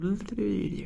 描述：bab呀学语的人
Tag: 咿呀学语 咿呀学语的人